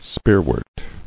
[spear·wort]